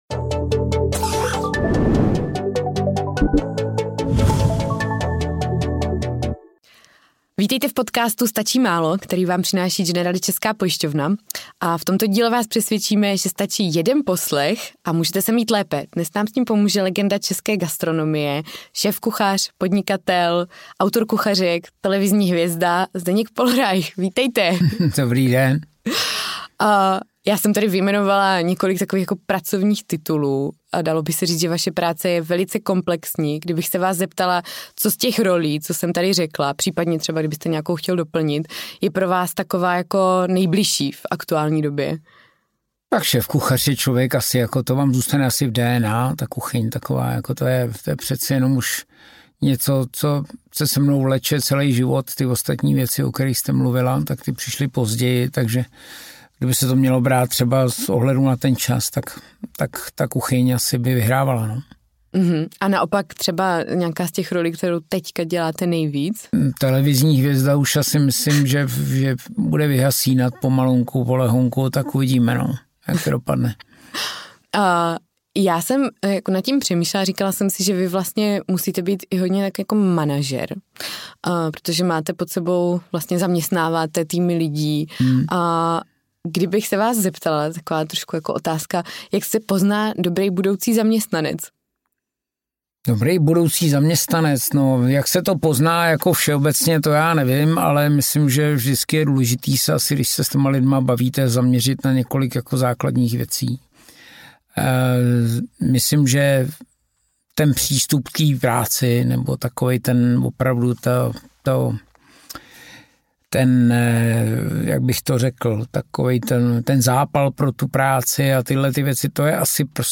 Zdeněk Pohlreich v našem rozhovoru mluví přímo a bez rukaviček. O proměnách gastronomického světa v posledních měsících, o obtížnostech podnikání i svých začátcích. Jak poznáte kvalitní restauraci doma i v zahraničí?